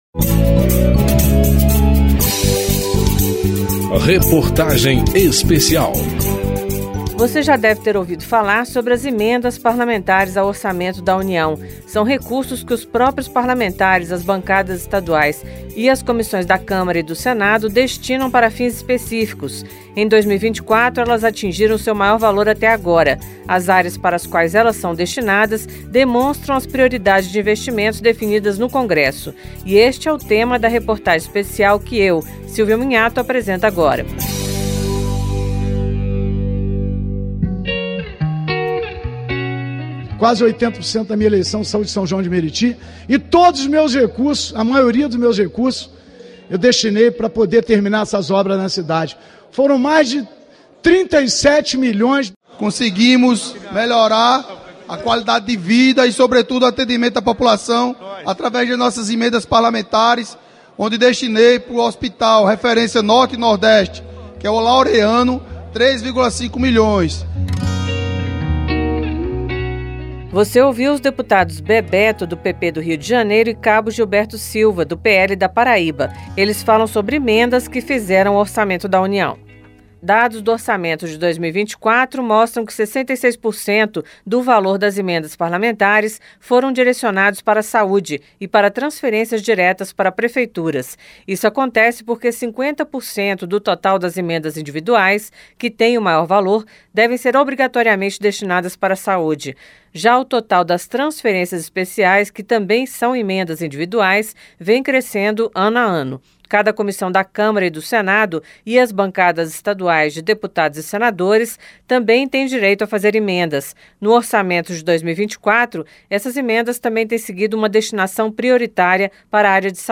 Reportagem Especial
Entrevistas nessa edição: os Bebeto (PP-RJ), Cabo Gilberto Silva (PL-PB), Emidinho Madeira (PL-MG), Josivaldo JP (PSD-MA),Sargento Fahur (PSD-PR), Luiz Gastão (PSD-CE) e Merlong Solano (PT-PI).